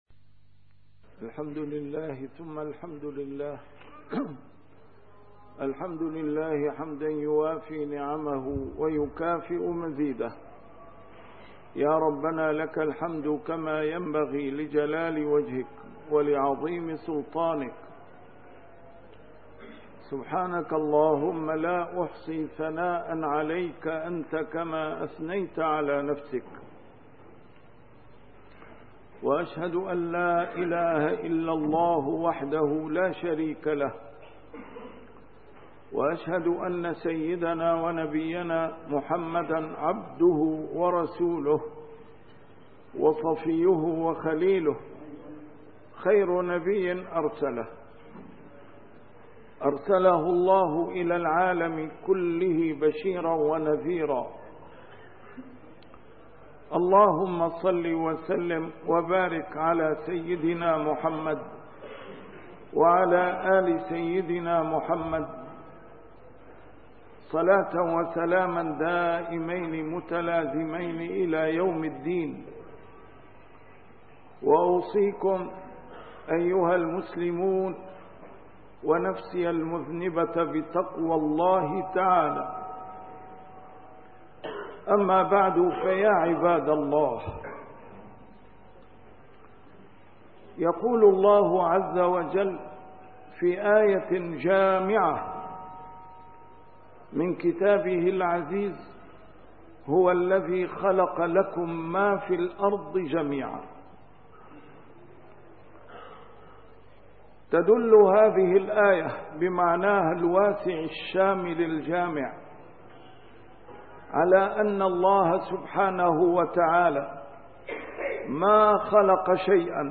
A MARTYR SCHOLAR: IMAM MUHAMMAD SAEED RAMADAN AL-BOUTI - الخطب - موقف الإسلام من المخترعات الحديثة